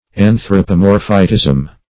Search Result for " anthropomorphitism" : The Collaborative International Dictionary of English v.0.48: Anthropomorphitism \An`thro*po*mor"phi*tism\, n. Anthropomorphism.